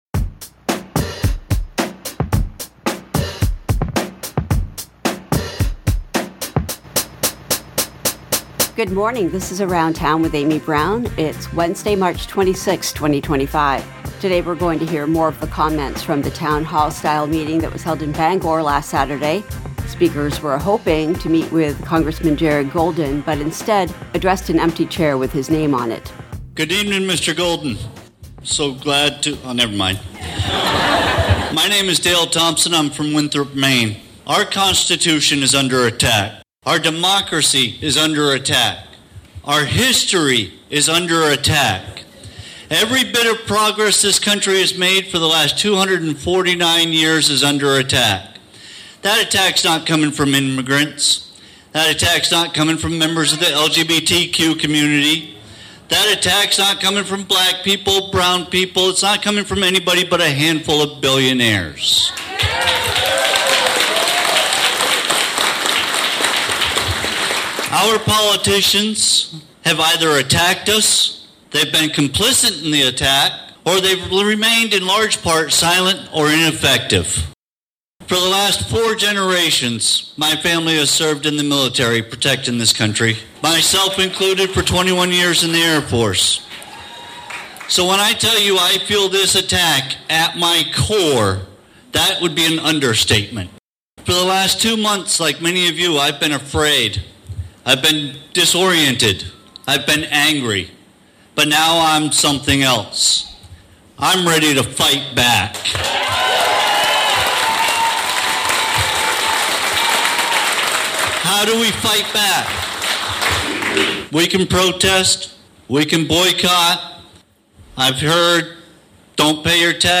Constituents speak at a Town Hall style meeting in Bangor on Saturday. Congressman Jared Golden was invited but did not attend, so those who spoke addressed an empty chair.